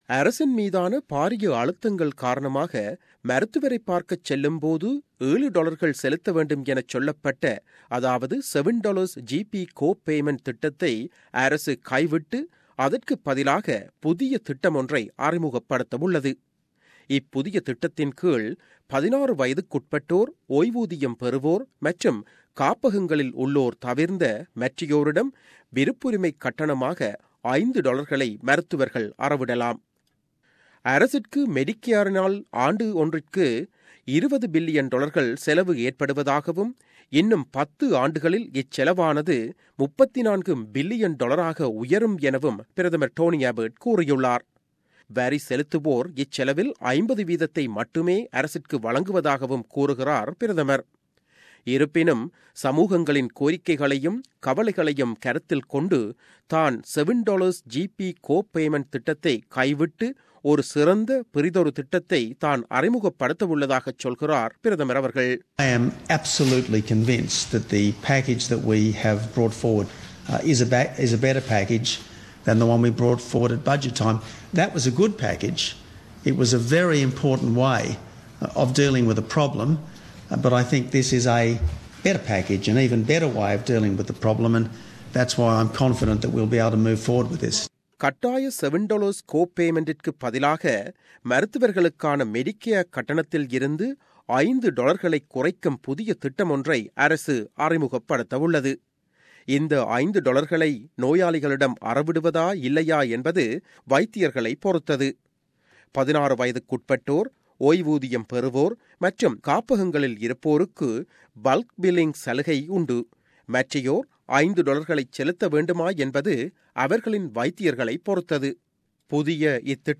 மருத்துவரைப் பார்க்கச் செல்லும் பொது செலுத்தவேண்டும் எனச் சொல்லப்பட்ட, அதாவது $7 GP co-payment திட்டத்தை அரசு கைவிட்டுள்ளது. ஆனால் $5 மாற்றுத் திட்டம் ஒன்றை முன்வைக்க உத்தேசித்துள்ளது அரசு. அது பற்றிய செய்தி விவர்னம்